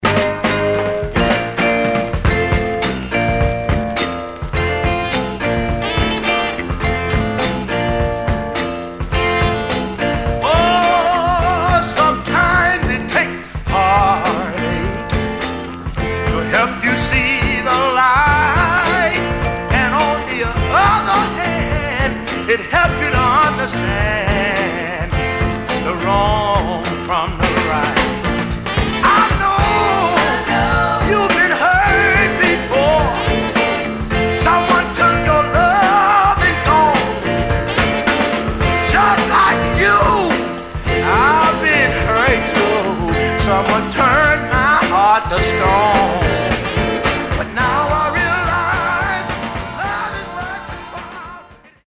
Condition Surface noise/wear Stereo/mono Mono
Soul